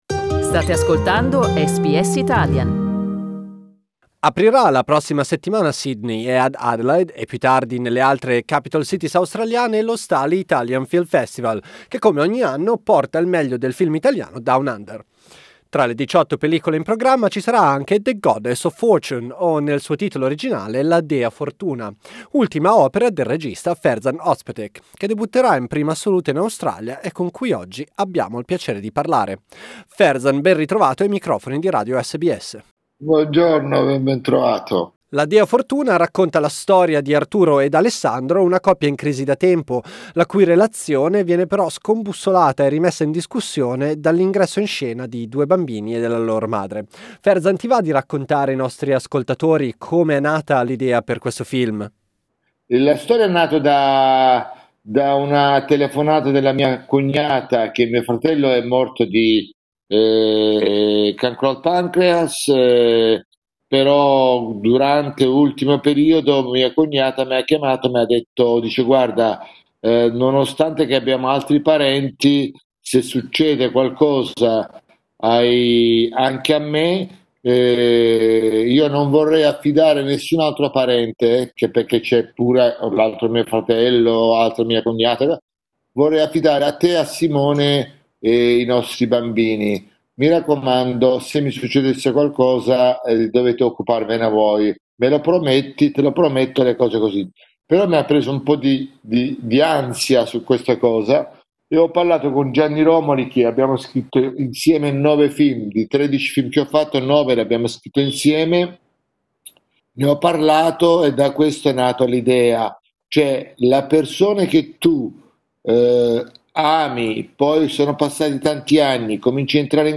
SBS Italian ha intervistato il regista Ferzan Ozpetek.